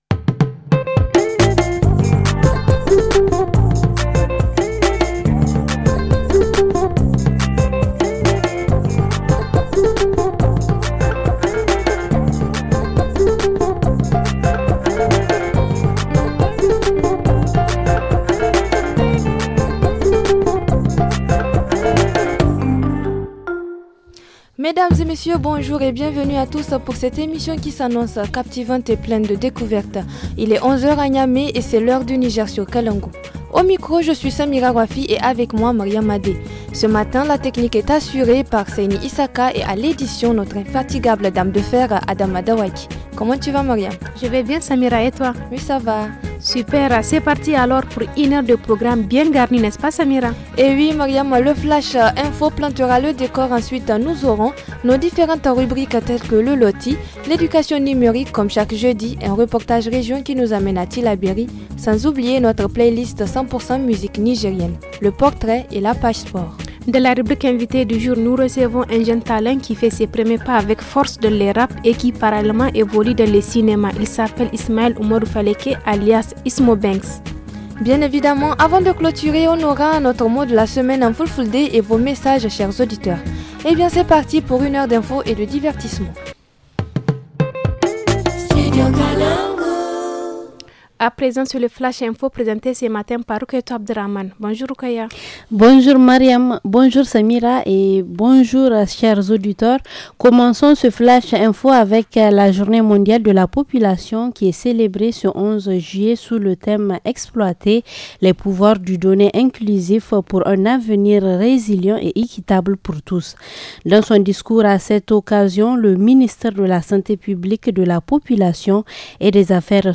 Playlist musicale